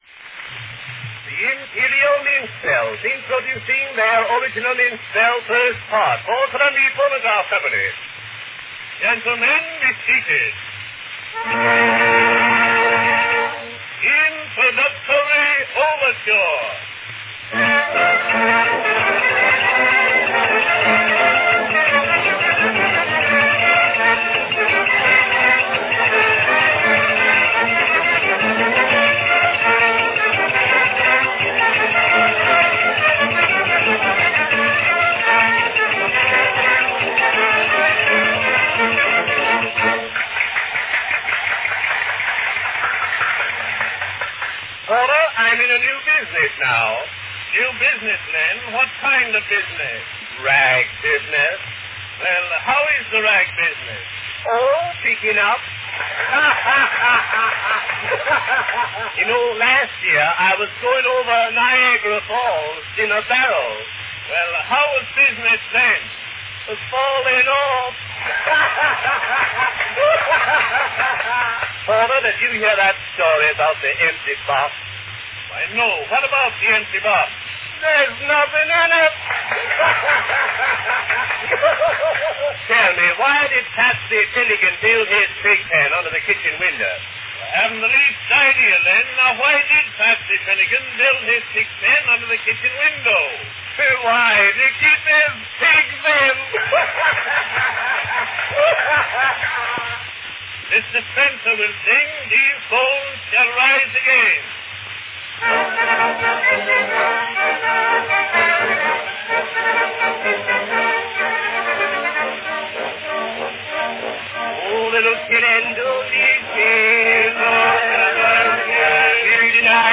Category Minstrels